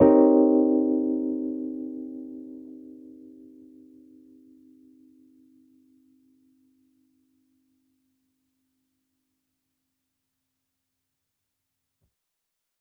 JK_ElPiano3_Chord-Cm7b9.wav